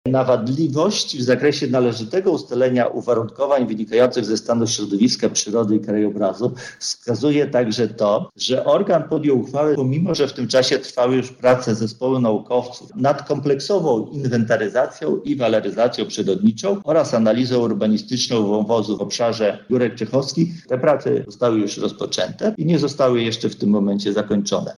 • mówi sędzia Grzegorz Grymuza.